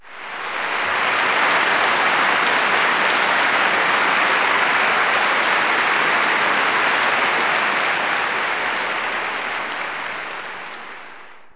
clap_039.wav